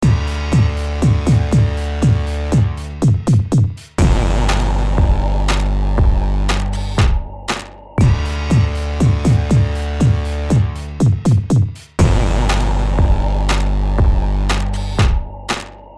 描述：小丑的悲惨槽
Tag: 120 bpm Weird Loops Groove Loops 2.68 MB wav Key : Unknown